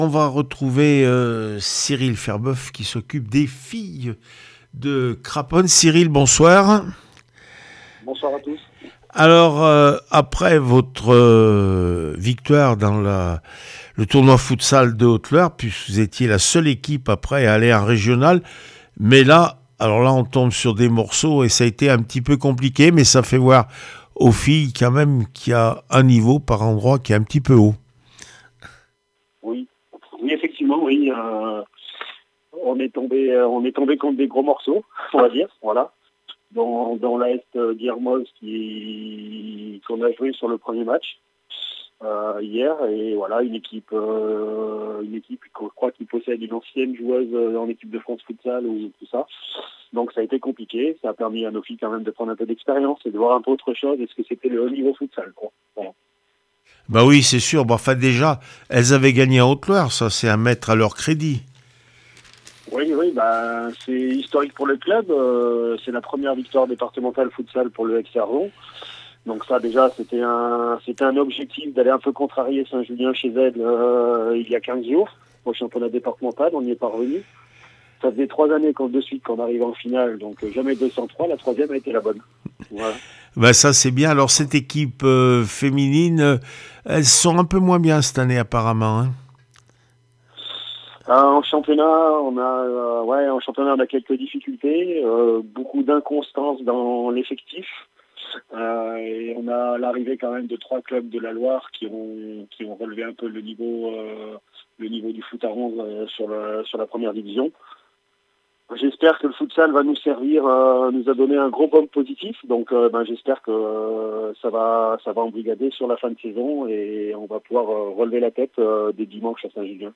24 février 2026   1 - Sport, 1 - Vos interviews